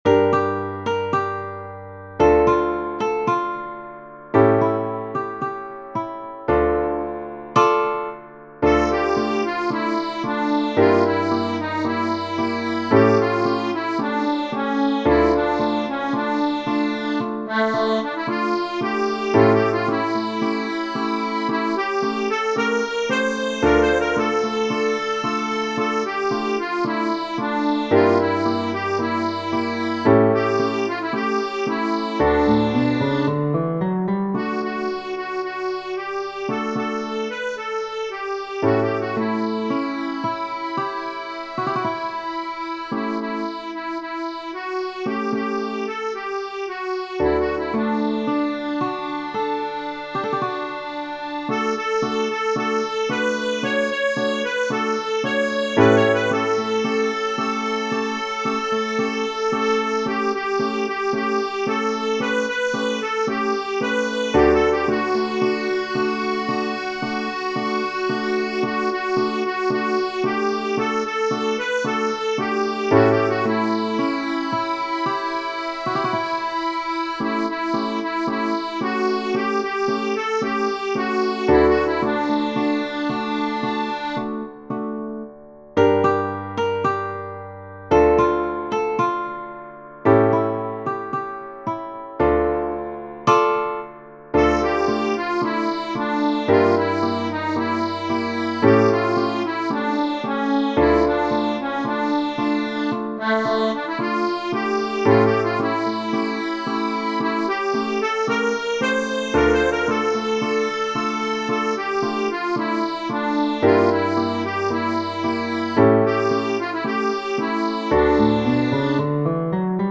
Genere: Ballabili
una canzone a ritmo di tango